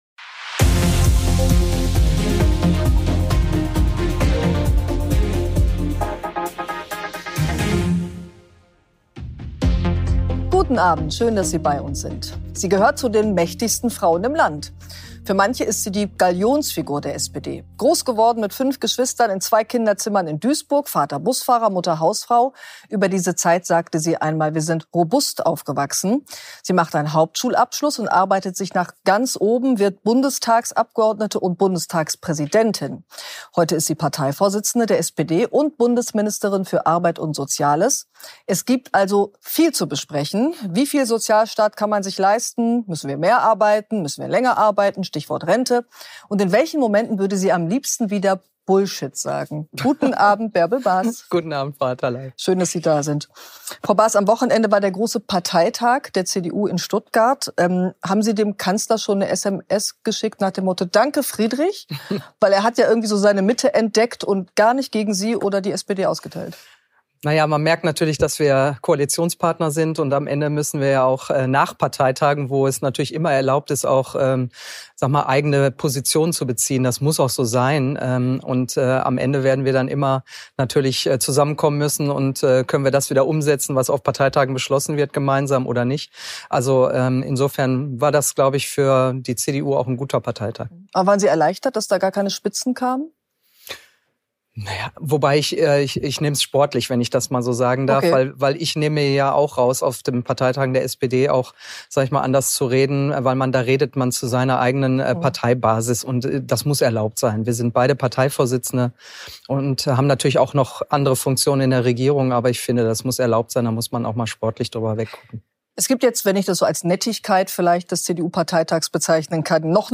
Arbeitsministerin Bärbel Bas ist zu Gast bei Pinar Atalay. Ein zentrales Gesprächsthema der Sendung ist die Zukunft der deutschen Rentenversicherung.